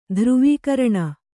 ♪ dhruvīkaraṇa